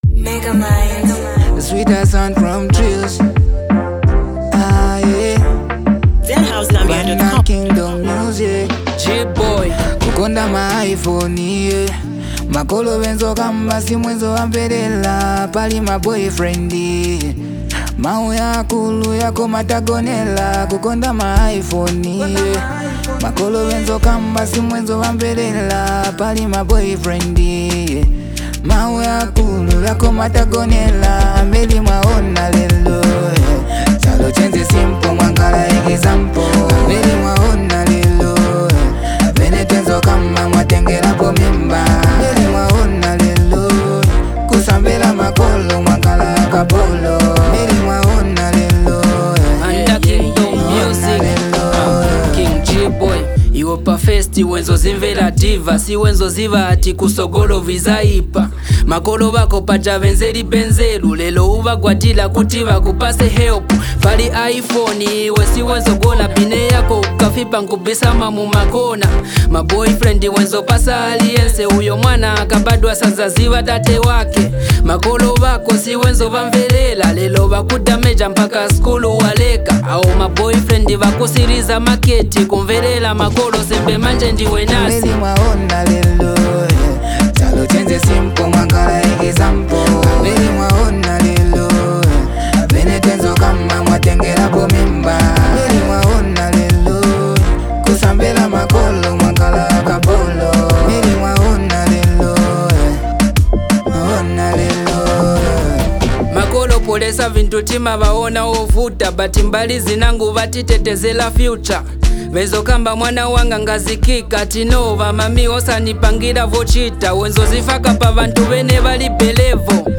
a smooth banger